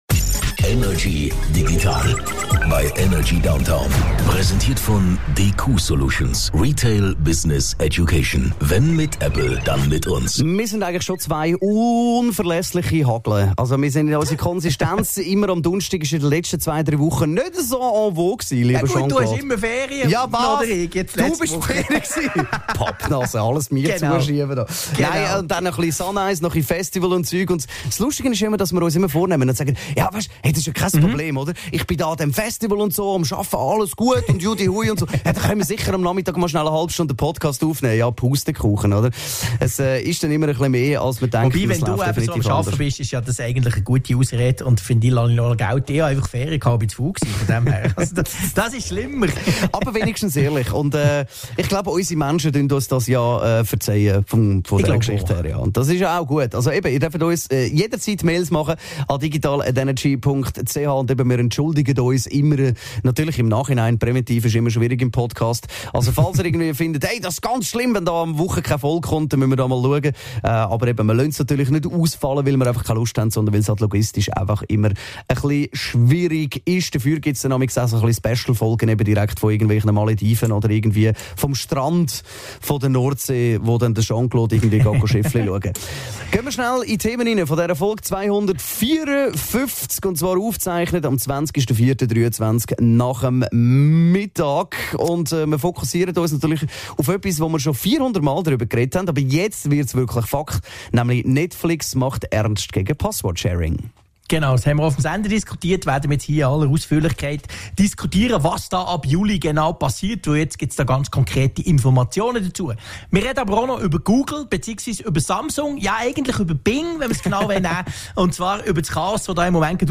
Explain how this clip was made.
aus dem HomeOffice